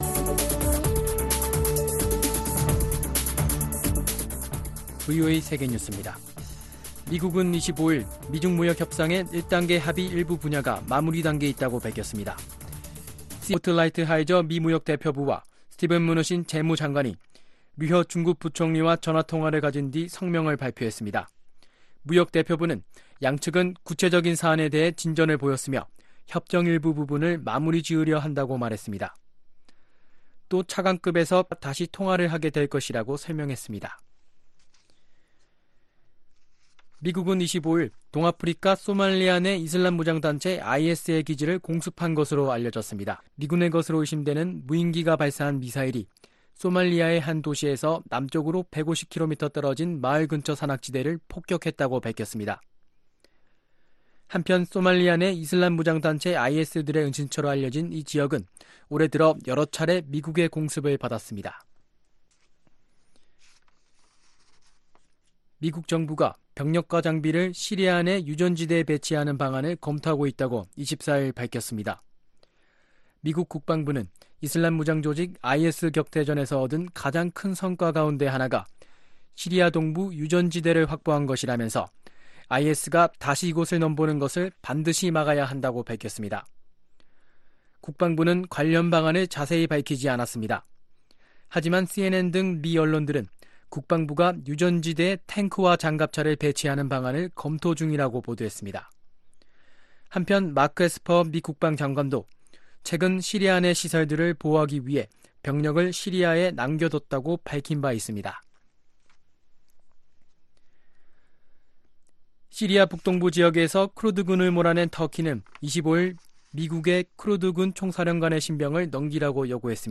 VOA 한국어 아침 뉴스 프로그램 '워싱턴 뉴스 광장' 2019년 10월 26일 방송입니다. 북한이 금강산 남측 시설 철거 문제를 서신교환을 통해 논의하자고 한국 정부에 통지문을 보냈습니다. 북한이 중국에 제안한 것으로 알려진 태양광 발전소 투자를 대가로 한 희토류 채굴권 양도는 유엔안보리 결의 위반이라는 지적이 나오는 가운데, 미국은 각국에 성실한 안보리 제재 이행을 강조했습니다.